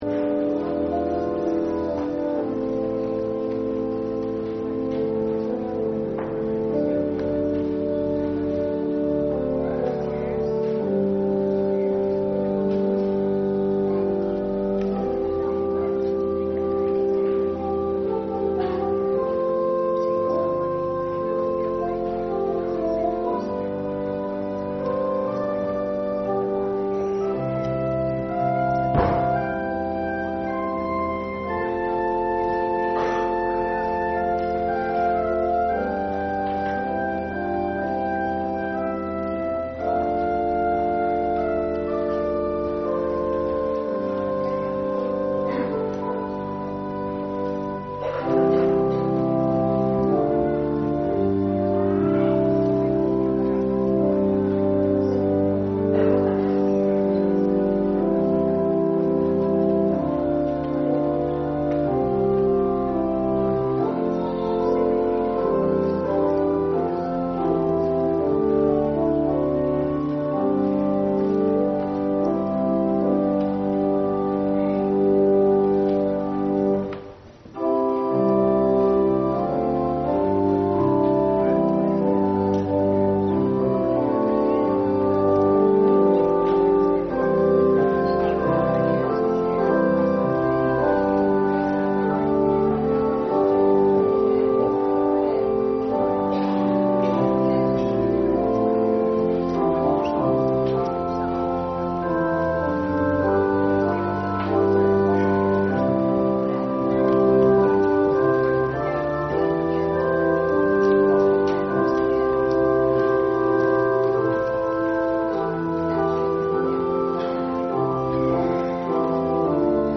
Ministry of the Word Judges 3:7-31God raises up saviours for his forgetful people. 1.
Service Type: Sunday Morning